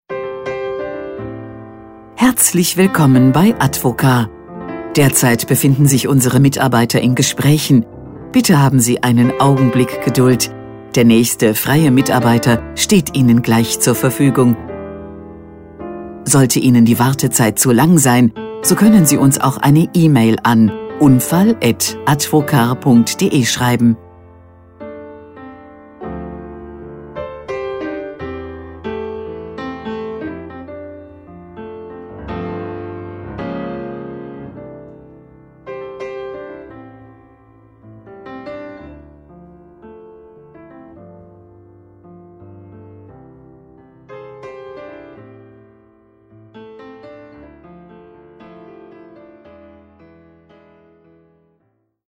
Telefonansage – Unfallregulierung & Schadensmanagement
AB außerhalb der Geschäftszeiten